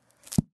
Звуки книги